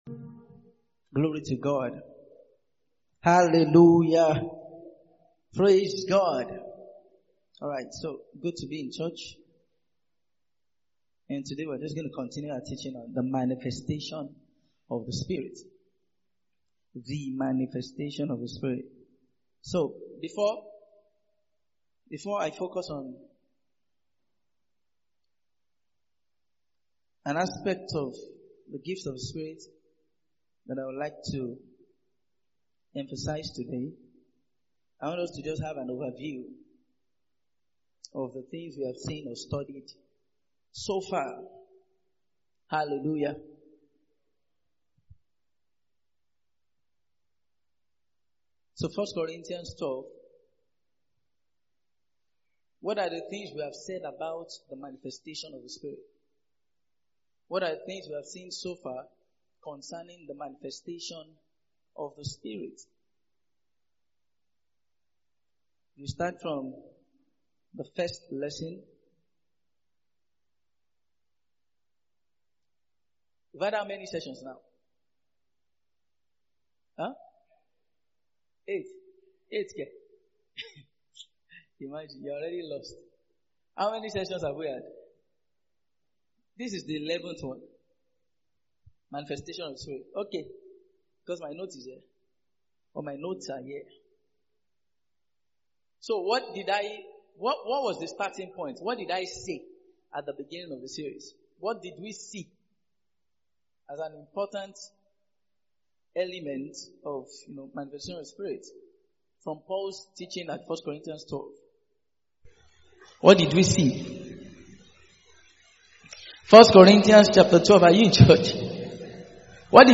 Mid-Week Sermons